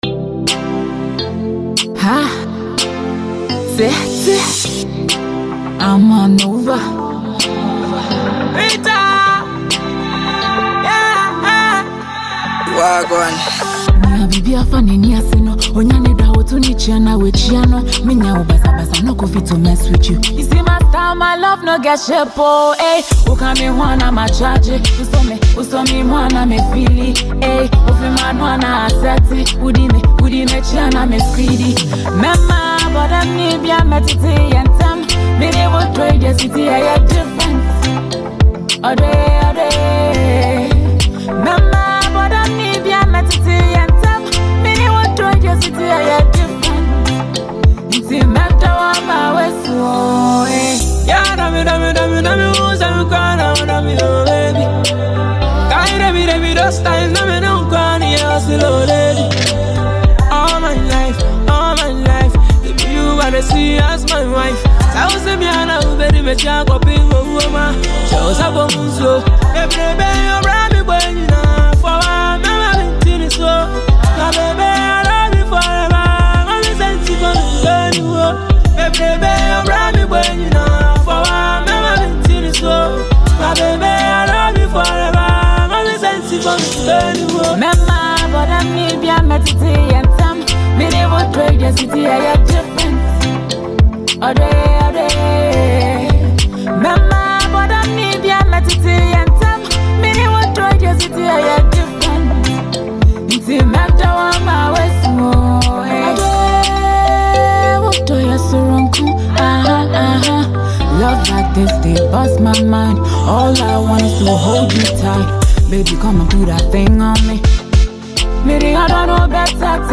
Ghanaian songstress